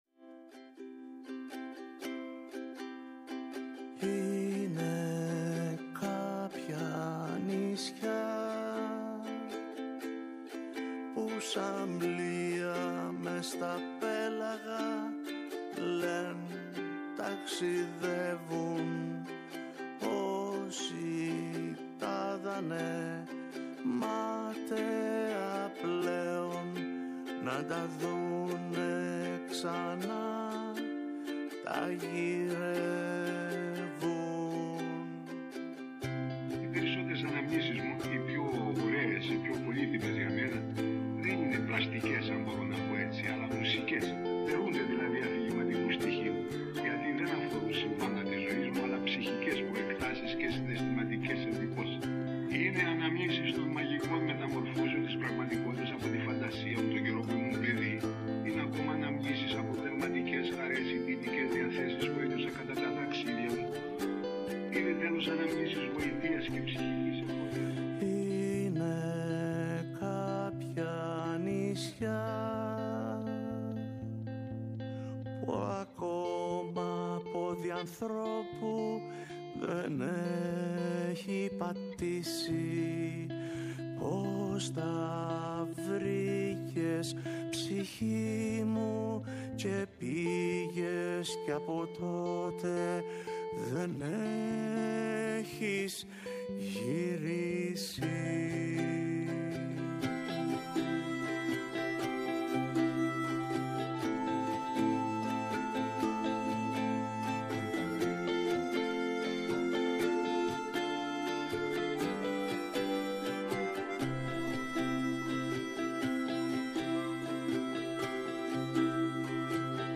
ΔΕΥΤΕΡΟ ΠΡΟΓΡΑΜΜΑ Παντος Καιρου Αφιερώματα Βιβλίο Μουσική Συνεντεύξεις